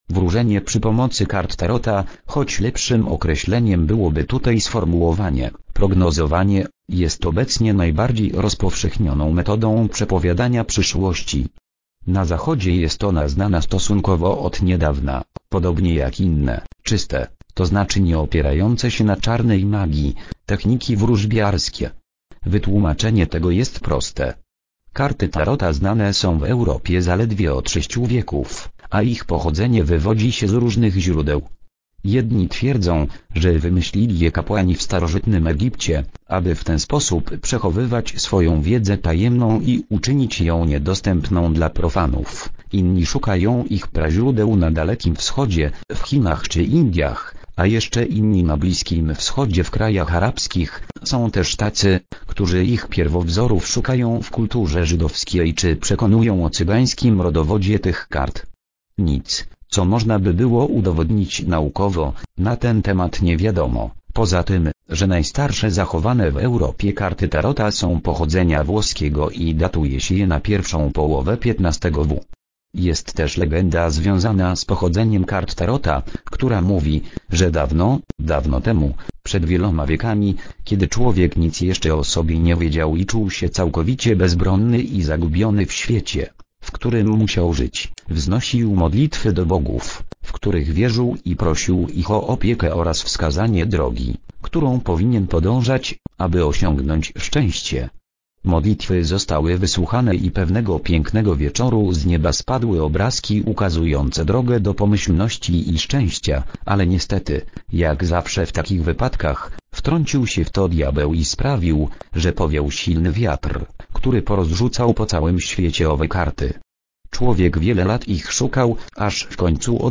Ksi��ka do s�uchania.